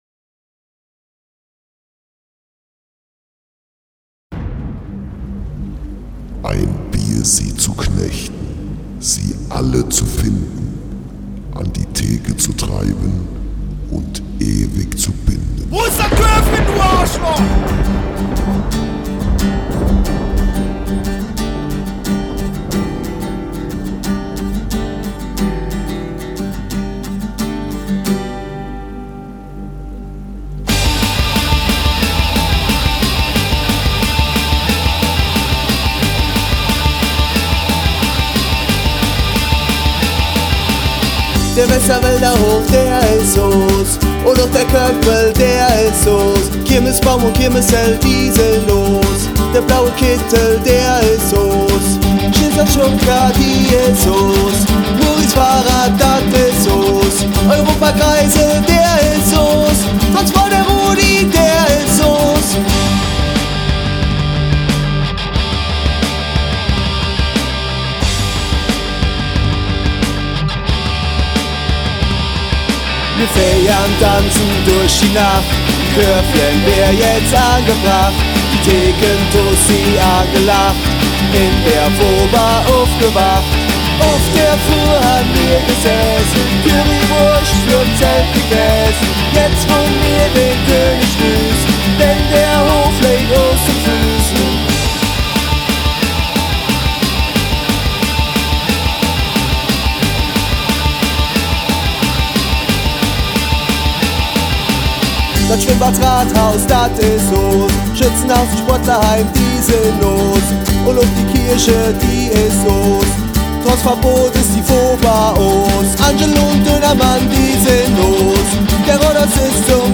Unsere hauseigene Kirmesjugend-Band begeistert mittlerweile schon seit einigen Jahren jedes Jahr erneut die Zuschauer.